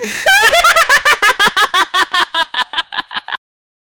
Laughter laugh at
laughter-laugh-at-aybk4vjg.wav